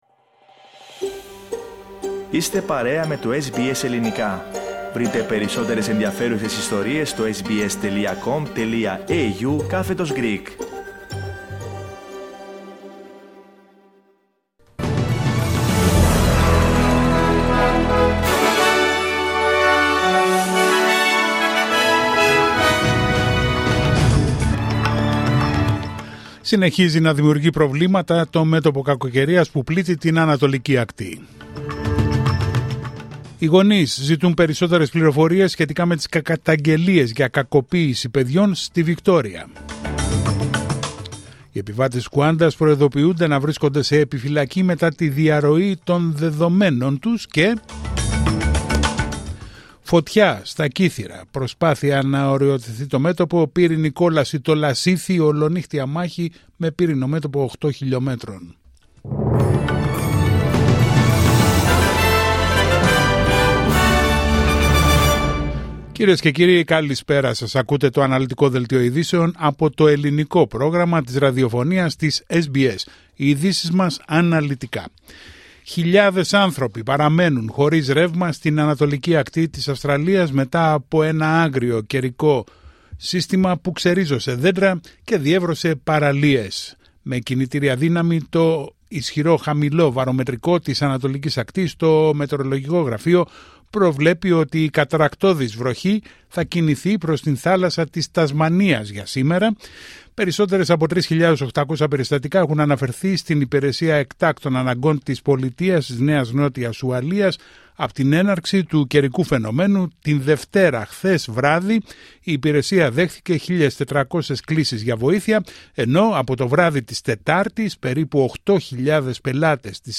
Δελτίο ειδήσεων Πέμπτη 3 Ιουλίου 2025